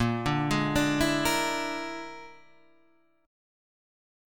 A#7b5 chord {6 7 6 7 9 6} chord